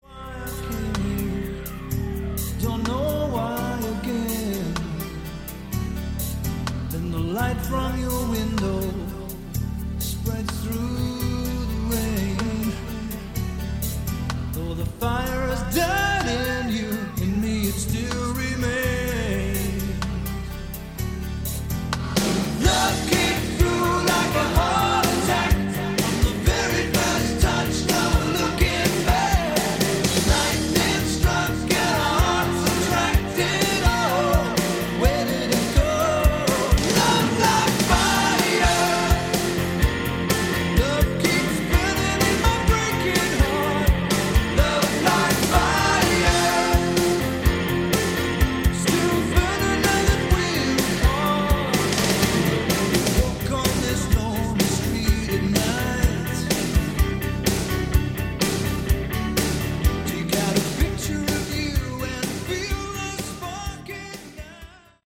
Category: AOR
Vocals
Guitar
Bass, Keyboards, Piano
Drums
A good british aor release,the production is not too bad